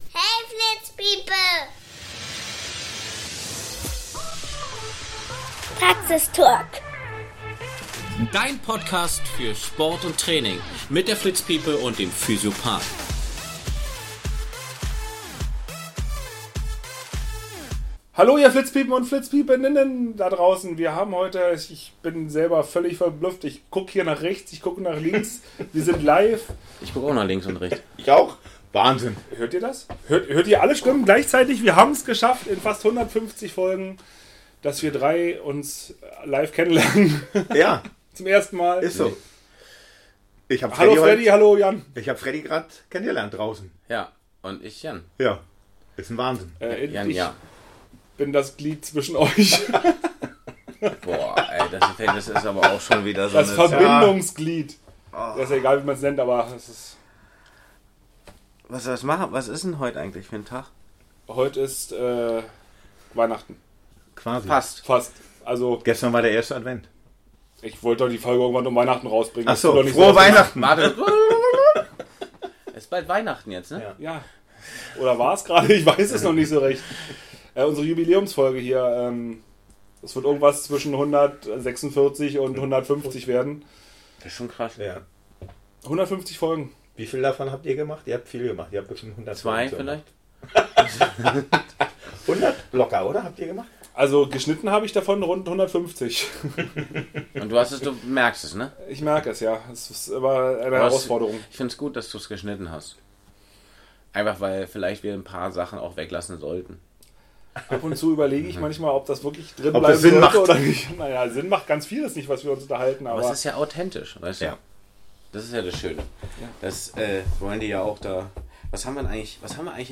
wir haben es geschafft 2025 alle drei zusammen im selben Raum Podcasten und ihr müsst es jetzt ausbaden